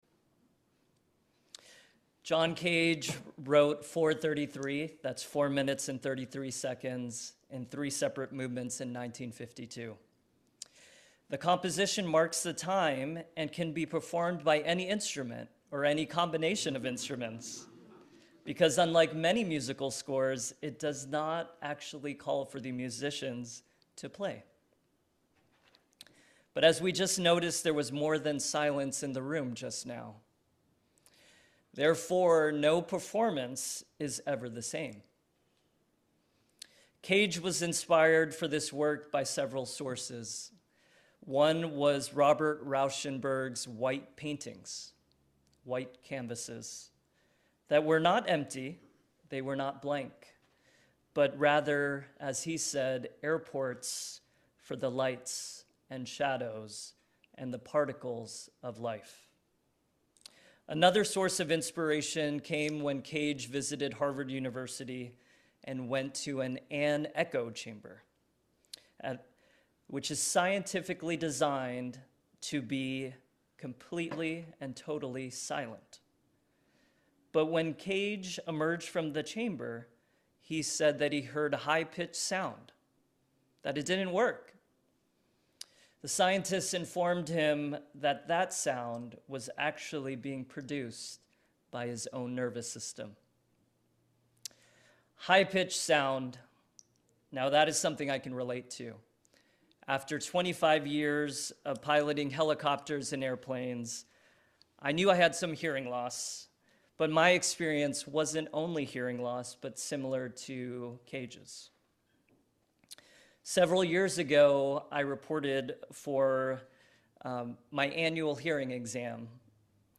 This sermon is a theological reflection that uses John Cage’s silent composition, 4’33”, to explore the profound spiritual significance of stillness and internal perception.